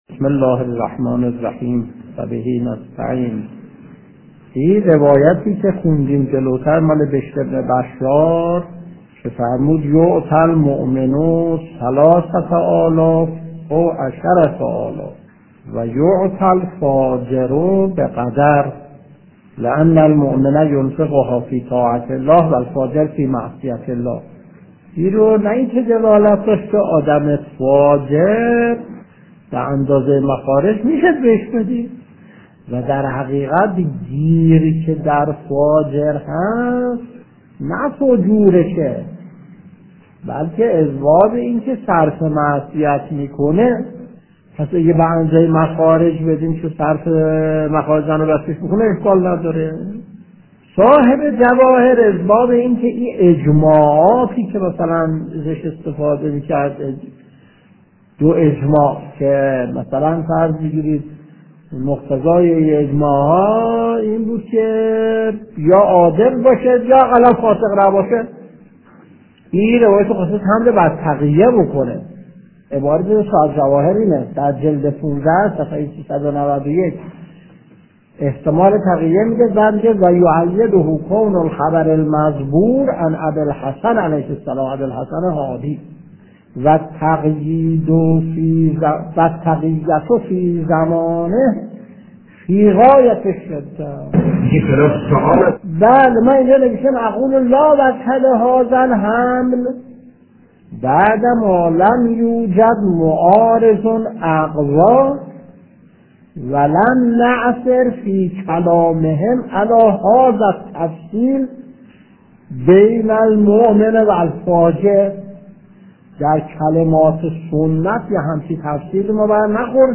درس 435 : (12/12/1368)